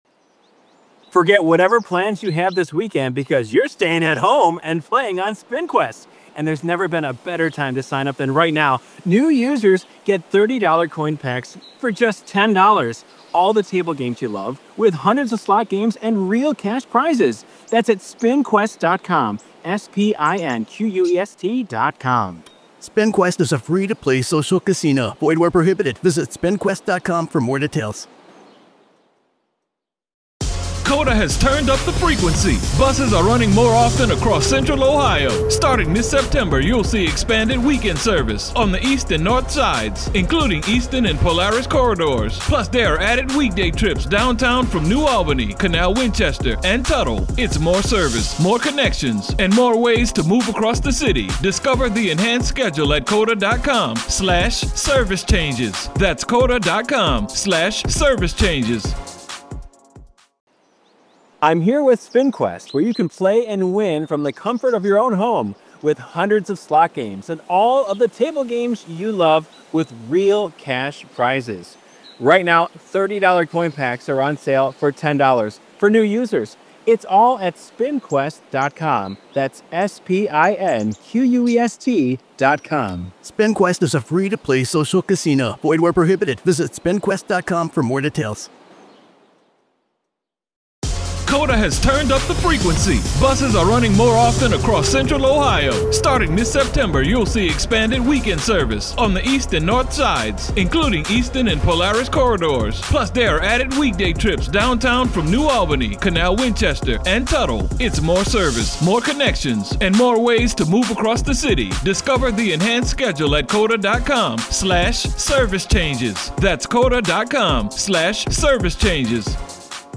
Raw Courtroom Audio